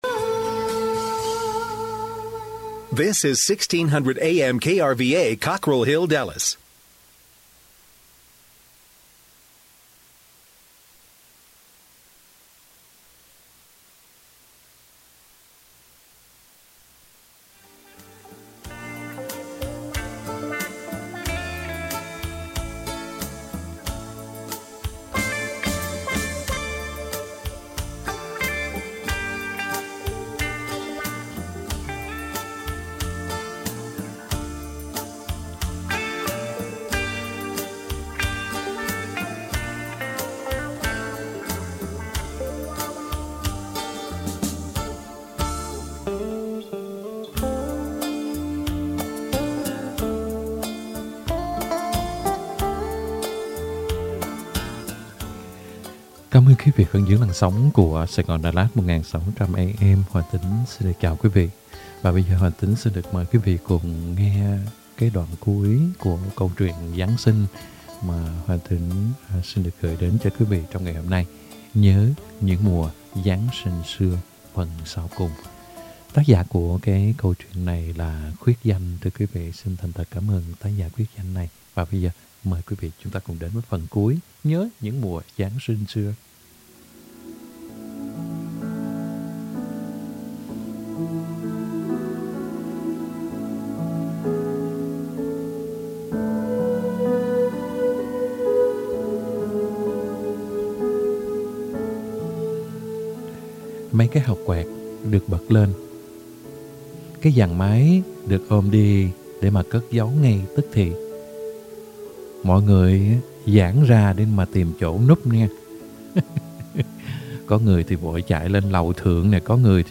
Đọc Truyện Ngắn = Nhớ những mùa Giáng Sinh xưa -(2 end) -12/28/2021 .